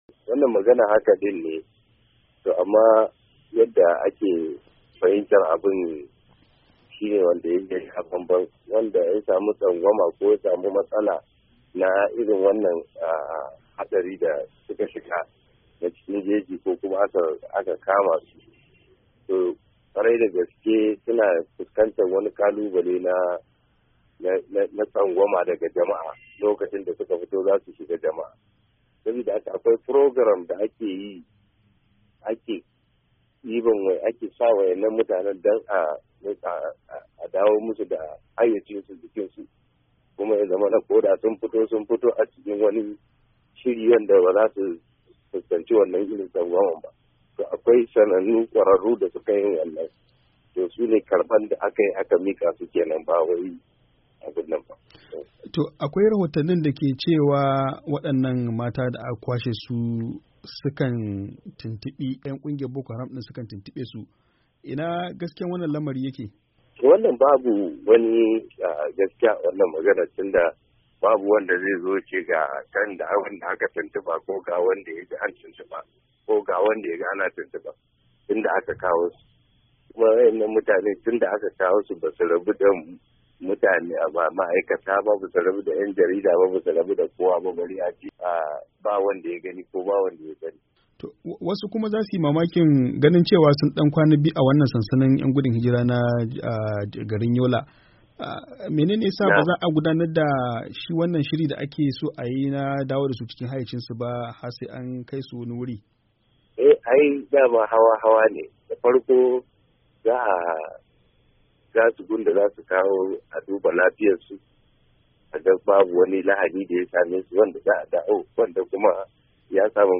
A wata hira da ma'aikacin sashin Hausa yayi da Muhammad Kanar shugaban hukumar bada agajin gaggawa ta NEMA. ya bayyana cewar maganar canza masu wurin lallai haka ne, to amma yadda ake fahimtar abin ya sha ban-ban da abin da ke guda na. saboda idan mutum ya sami kansa cikin wani mawuyacin hali na tsangwama ko hadari da suka shiga, to lallai akwai matsalar kalubalen tsangwama daga jama'a.